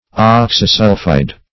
Search Result for " oxysulphide" : The Collaborative International Dictionary of English v.0.48: Oxysulphide \Ox`y*sul"phide\, n. (Chem.) A ternary compound of oxygen and sulphur.